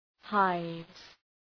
Προφορά
{haıvz}